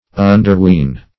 Underween \Un`der*ween"\